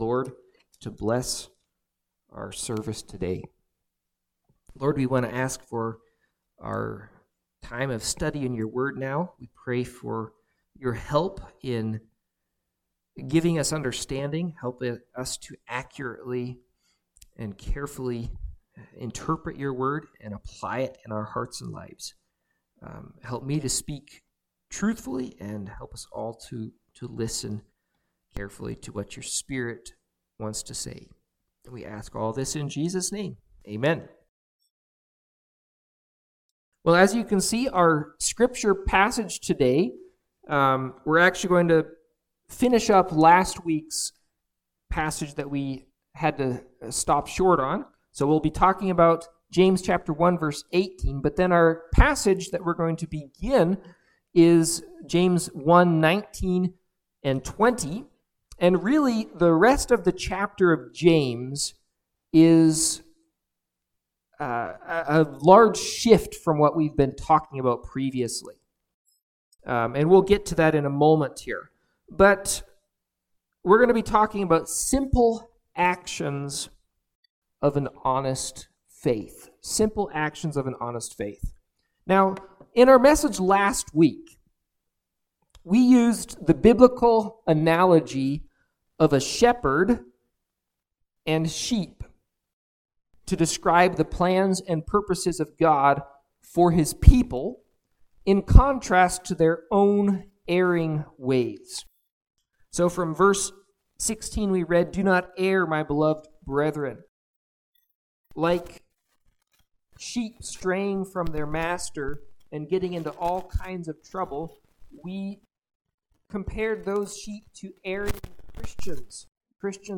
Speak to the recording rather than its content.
Service Type: Morning Sevice